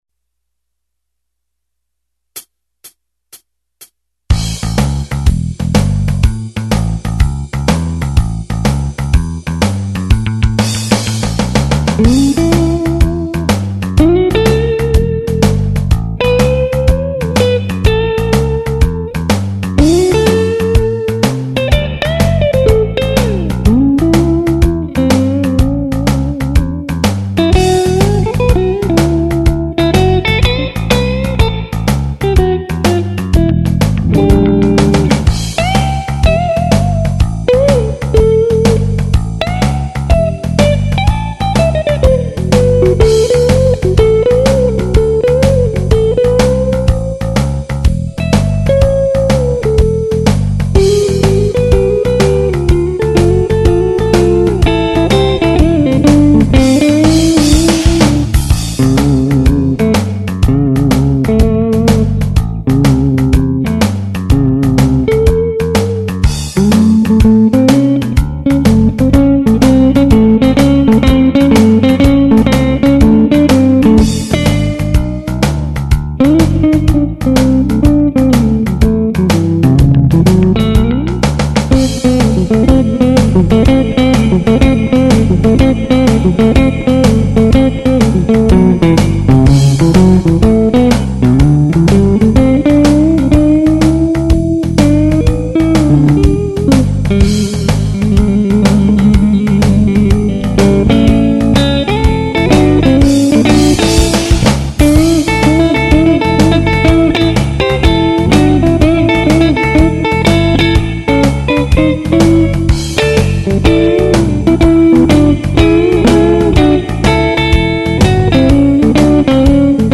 Without a screamer - can you hear the tube?
(Ich wollte unbedingt mal tappen - is auch gnadenlos schiefgegangen :-)))